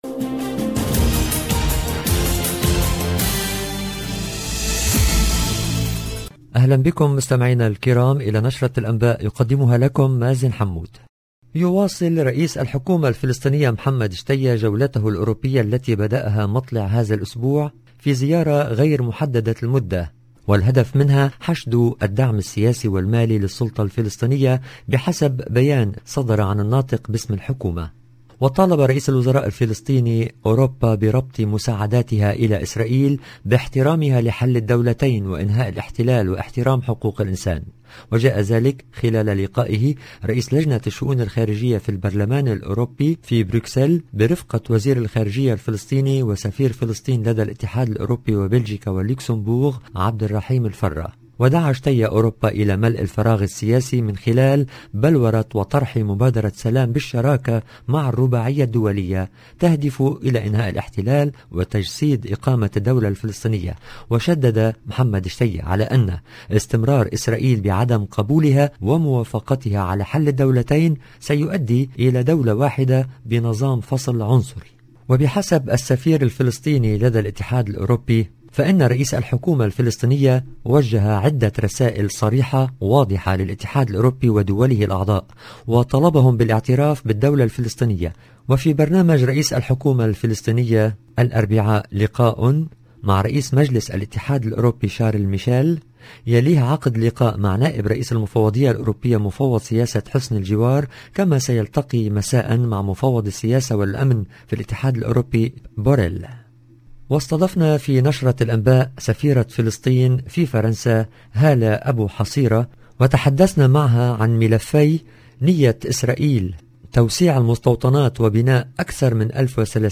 EDITION DU JOURNAL DU SOIR EN LANGUE ARABE